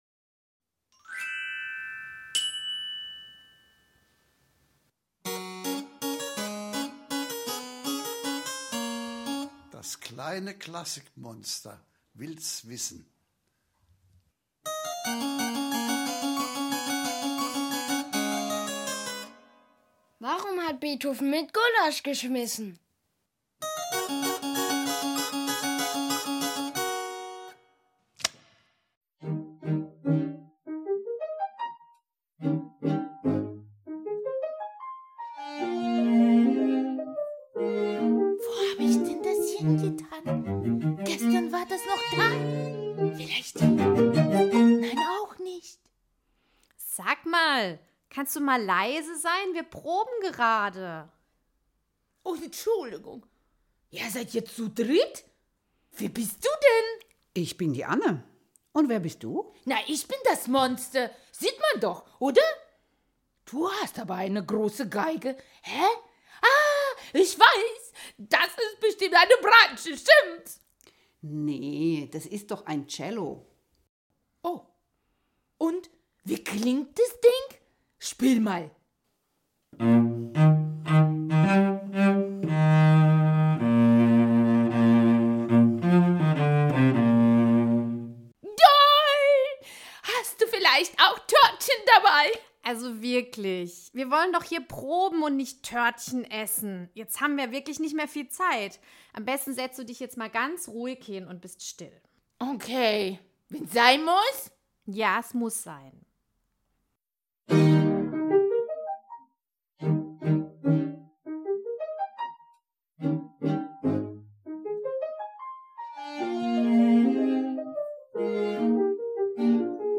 probt das Es-Dur Klaviertrio, Beethovens offizielles Opus 1, gewidmet dem Fürst Lichnowsky.